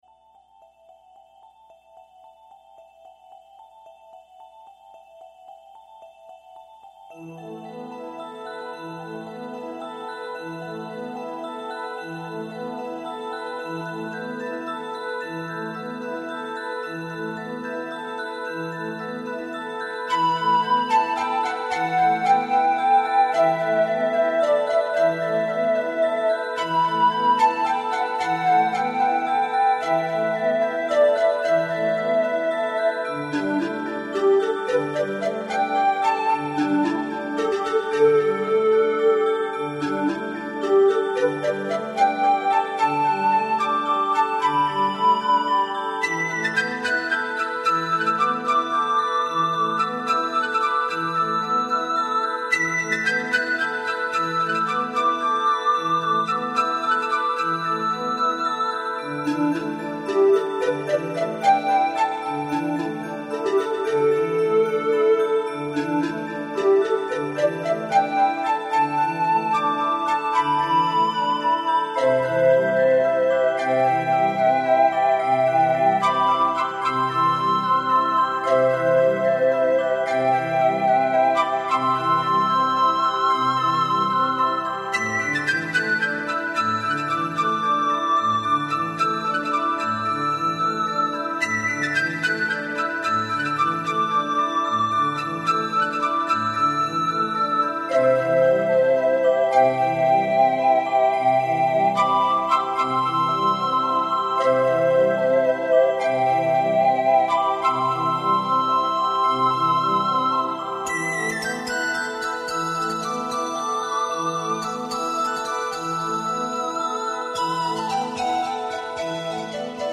Often ethereal